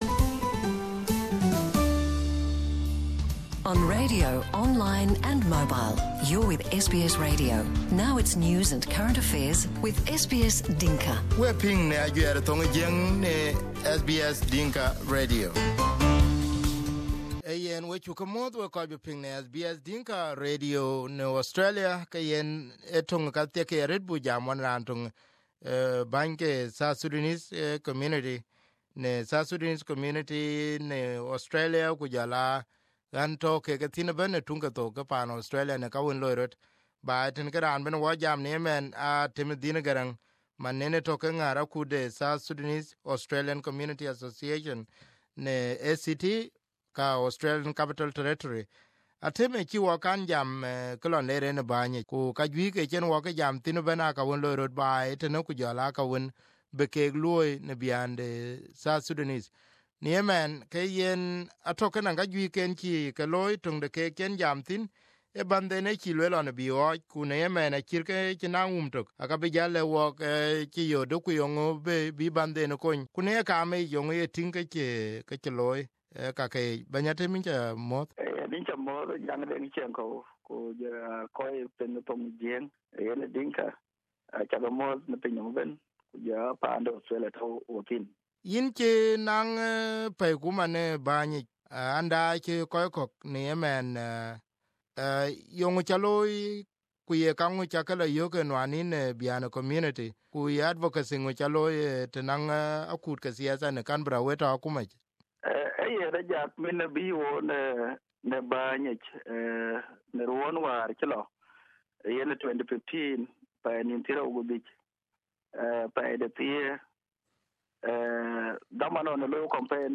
This is his interview on SBS Dinka Radio